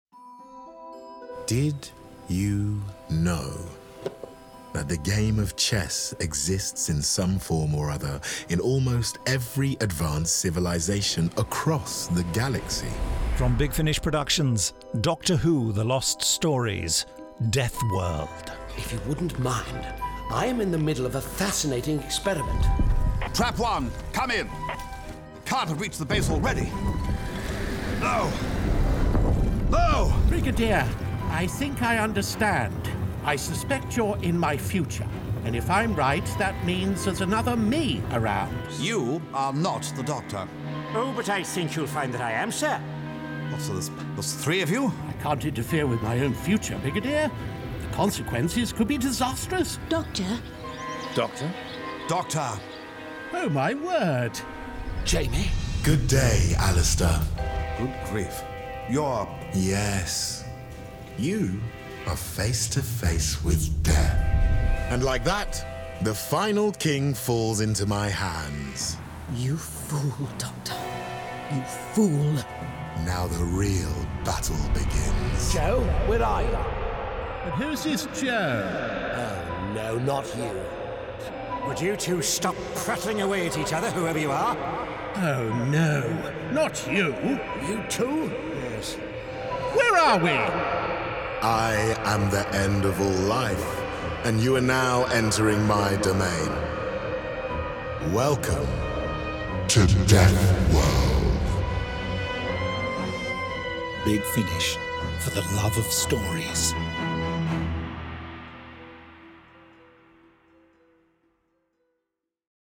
Award-winning, full-cast original audio dramas
Starring Jon Culshaw Frazer Hines